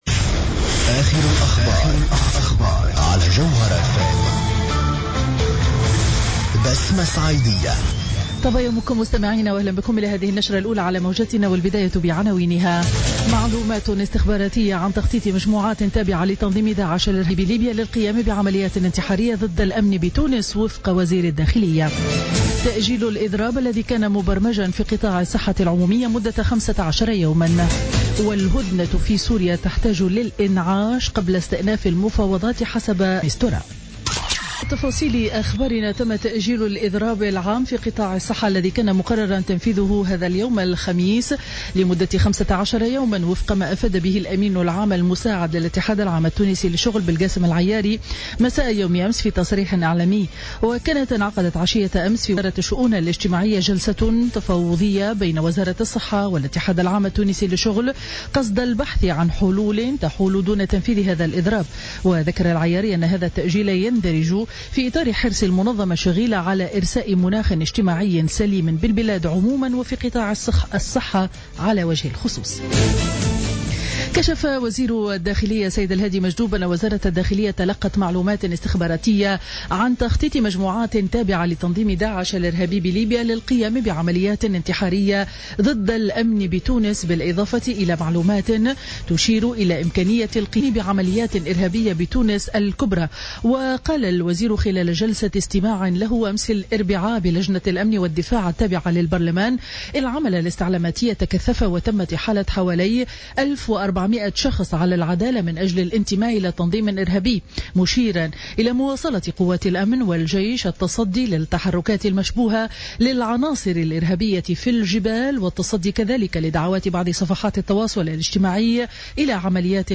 نشرة أخبار السابعة صباحا ليوم الخميس 28 أفريل 2016